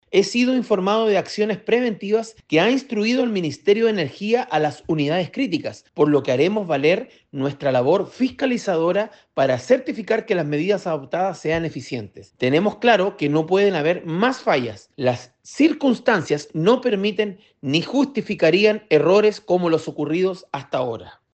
Su par en la Cámara Baja, el diputado UDI Marco Antonio Sulantay, dijo que la incertidumbre es justificada considerando las falencias que ha mostrado el sistema, destacando el rol fiscalizador que debe operar.